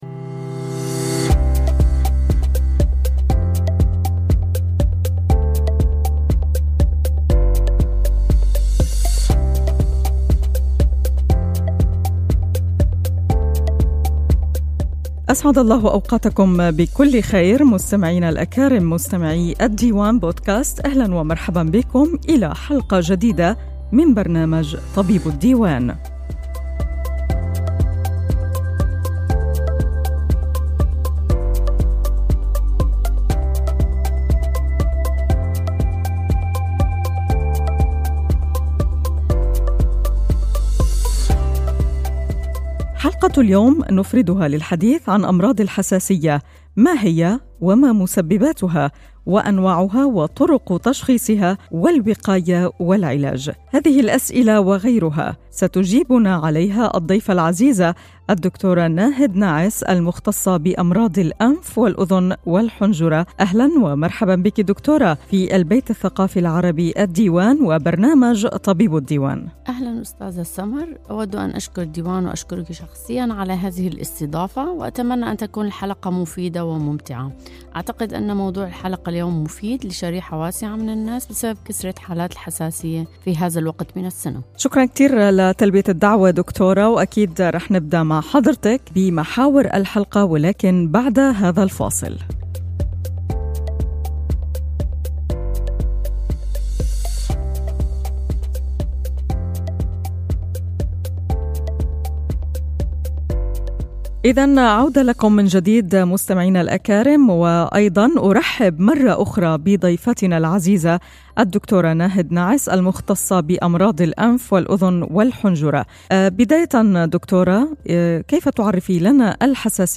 Interessante und nützliche Tipps sollen zu verschiedenen medizinischen Themen besprochen werden. In dieser Podcast-Reihe werden Ärzte aus den unterschiedlichen Fachrichtungen bei wöchentlichen Treffen interviewt.